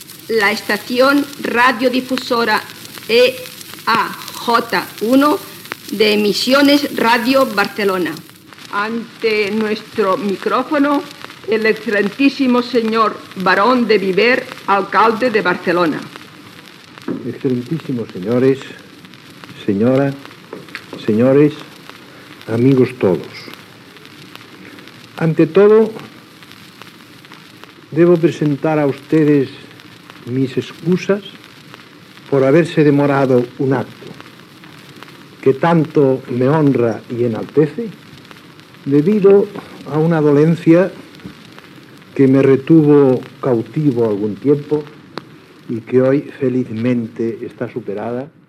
Recreació de l'emissió inaugural: Identificació de l'emissora i paraules de l'alcalde de Barcelona Darius Rumeu i Freixa, segon baró de Viver.
A l'any 1924 Darius Rumeu i Freixa tenia 38 anys i la veu que sentim a l'enregistrament sembla la d'un home molt més gran (va morir el 30 de gener de 1970).
Podrien haver estat extretes d'algun acte oficial dels anys 1940 o 1950.